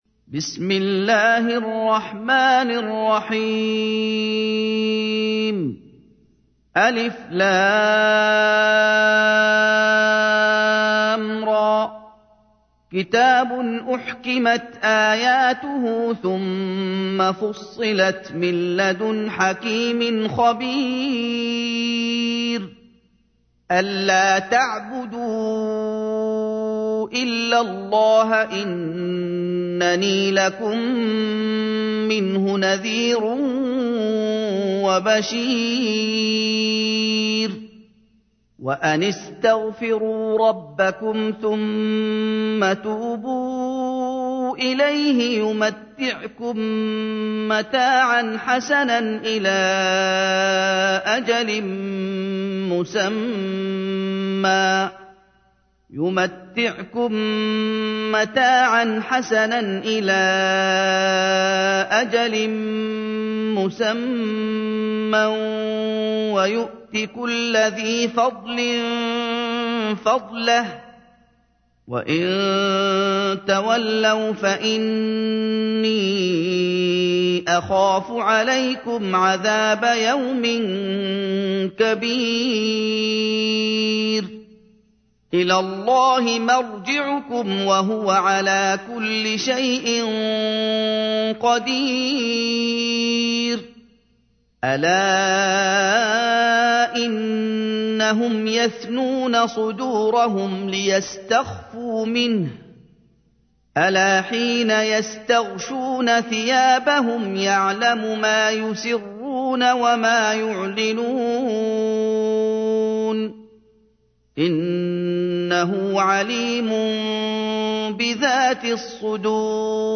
تحميل : 11. سورة هود / القارئ محمد أيوب / القرآن الكريم / موقع يا حسين